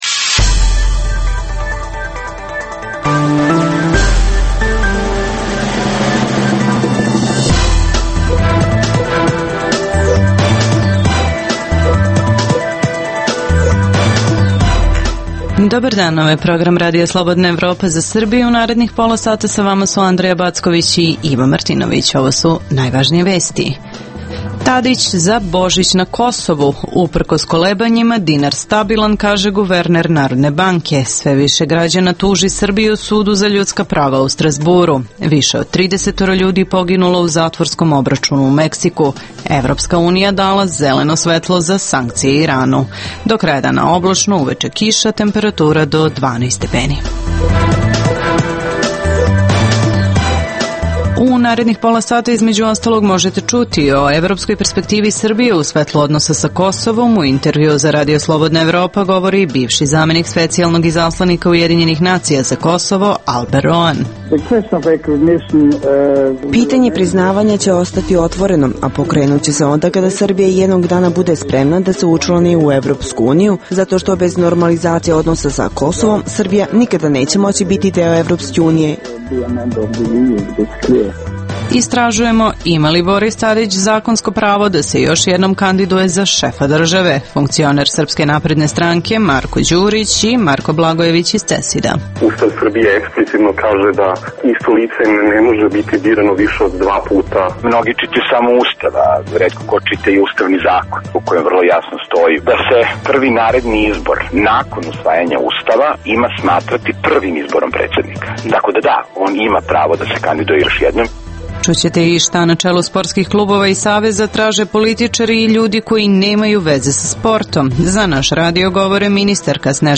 U emisiji poslušajte: - O evropskoj perspektivi Srbije u svetlu odnosa sa Kosovom u intervjuu za RSE govori bivši zamenik specijalnog izaslanika UN za Kosovo Alber Rohan. - Istražujemo ima li Tadić zakonsko pravo da se još jednom kandiduje za šefa države?